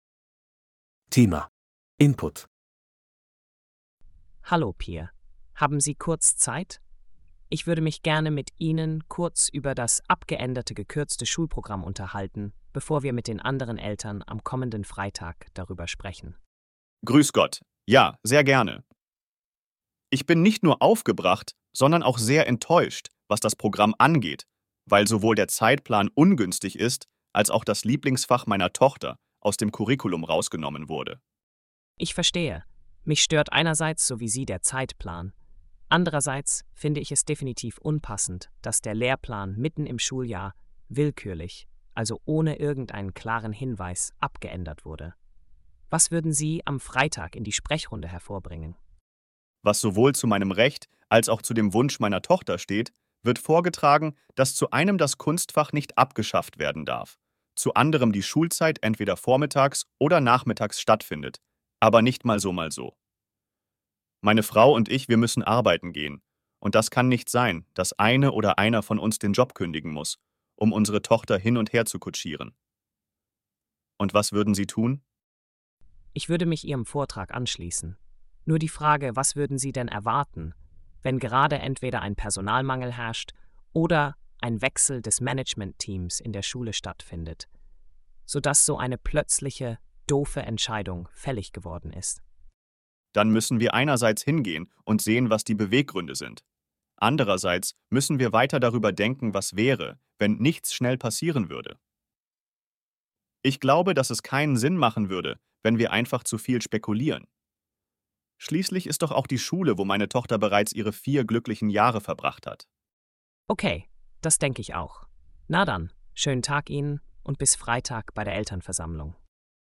Hörtext für die Dialoge bei Aufgabe 4